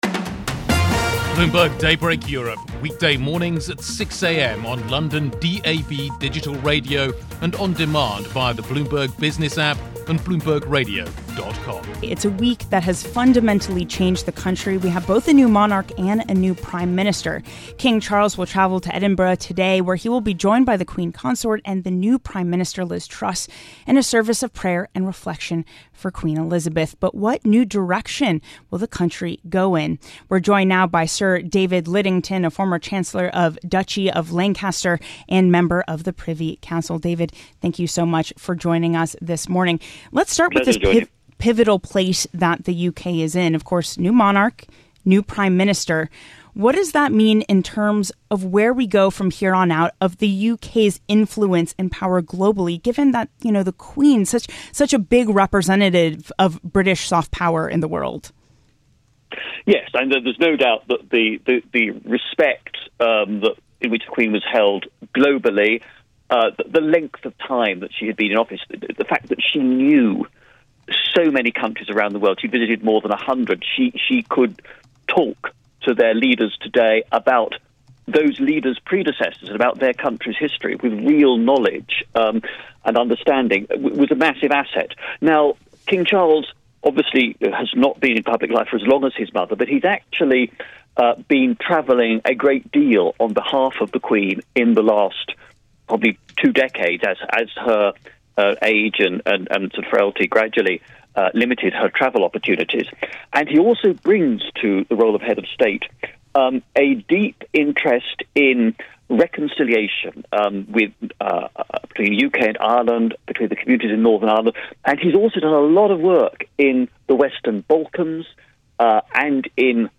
Former Cabinet Office Minister, David Lidington, says removing Tom Scholar as permanent secretary to the Treasury was a bad decision by the Prime Minister Liz Truss.